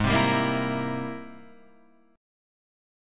G# Augmented Major 9th
G#+M9 chord {4 3 5 3 5 6} chord